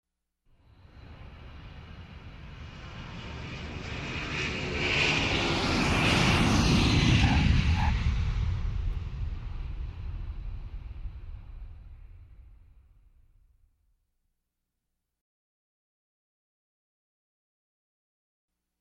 Звуки самолетов
Звук посадки самолета, затем визг тормозов приземление завершено